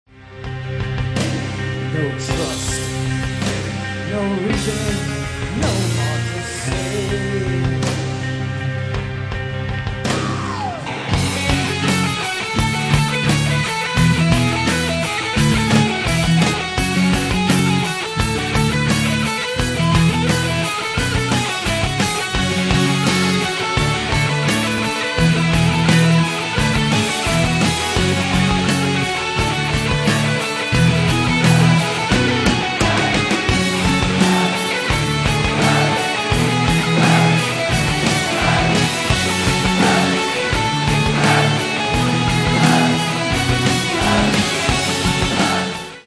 All the sound clips are live.